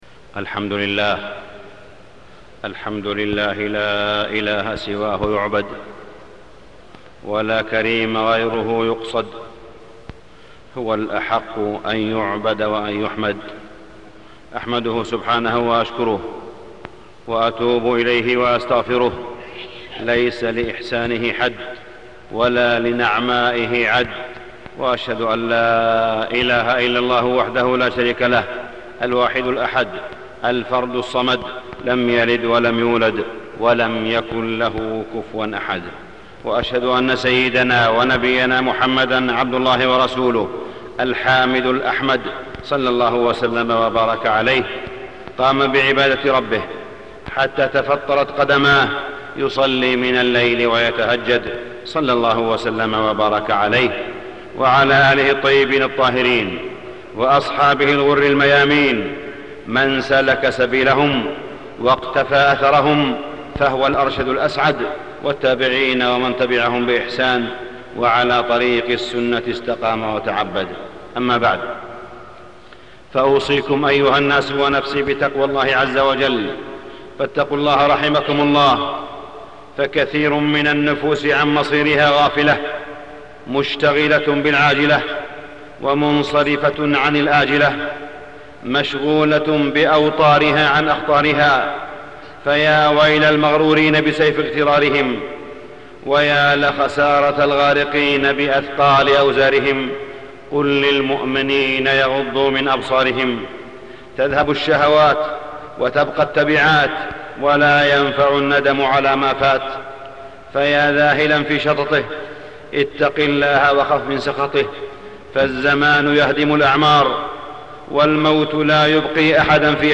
تاريخ النشر ٢٥ رمضان ١٤٢٦ هـ المكان: المسجد الحرام الشيخ: معالي الشيخ أ.د. صالح بن عبدالله بن حميد معالي الشيخ أ.د. صالح بن عبدالله بن حميد بين أيديكم شهر مبارك